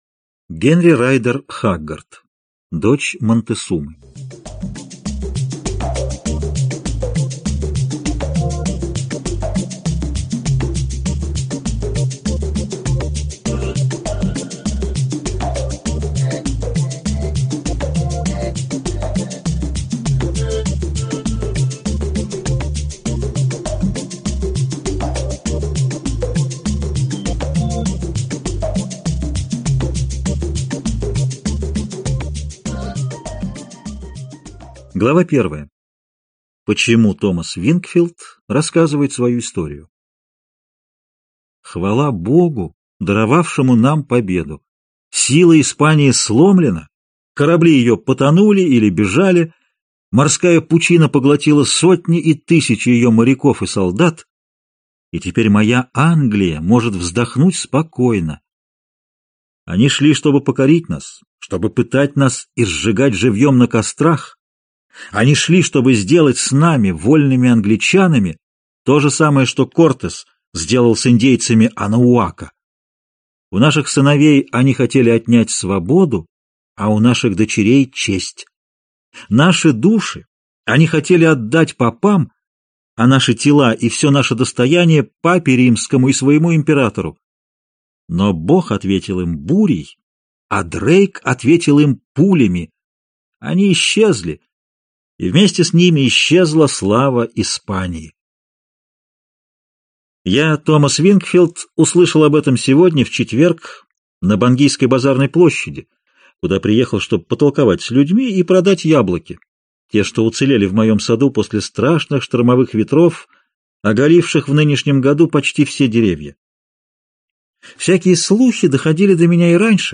Аудиокнига Дочь Монтесумы | Библиотека аудиокниг